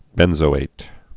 (bĕnzō-āt)